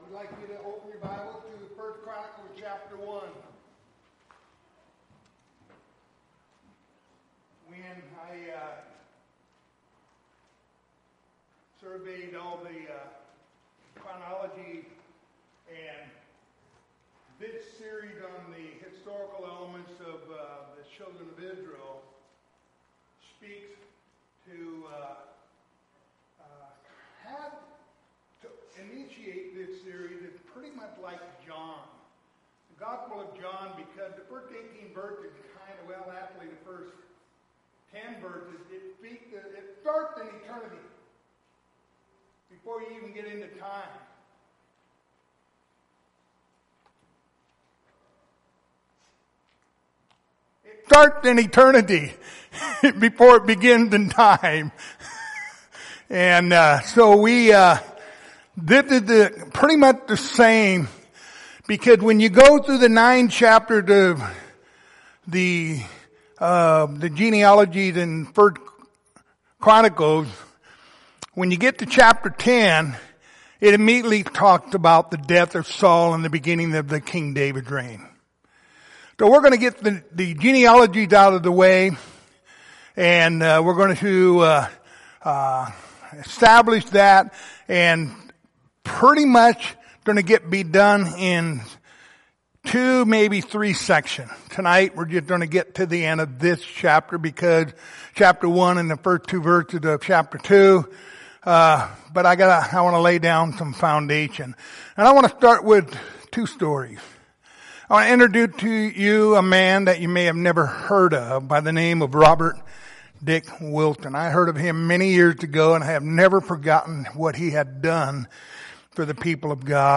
Passage: 1 Chronicles 1:1-28 Service Type: Wednesday Evening